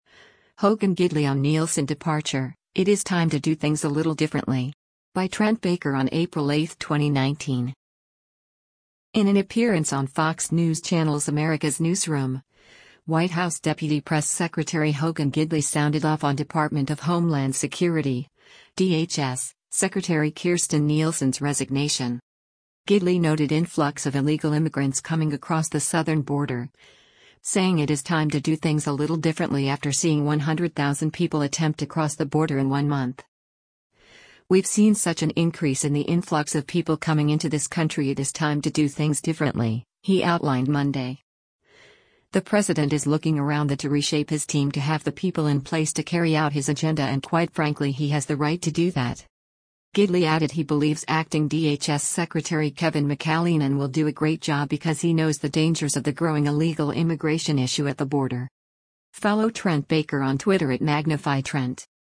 In an appearance on Fox News Channel’s “America’s Newsroom,” White House deputy press secretary Hogan Gidley sounded off on Department of Homeland Security (DHS) Secretary Kirstjen Nielsen’s resignation.